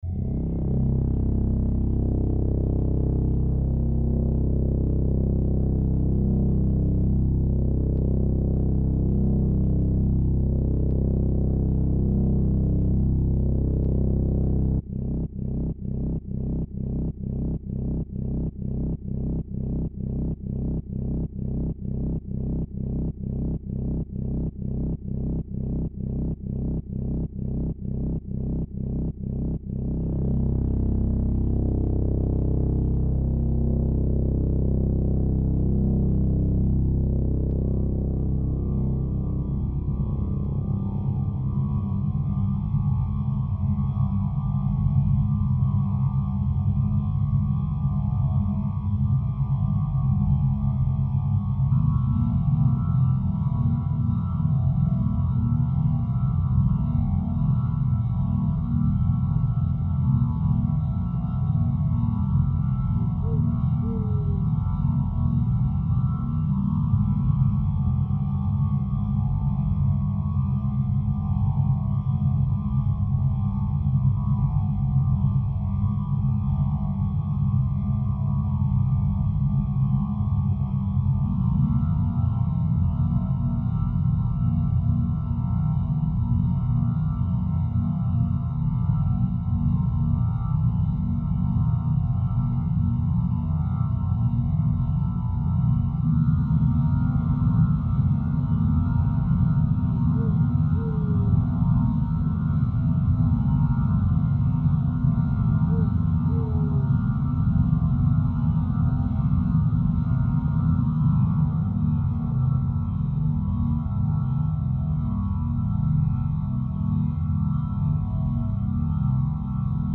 I’m still a beginner, but I am embarrassed to say my first thought was to make some ambient drone .
owls.mp3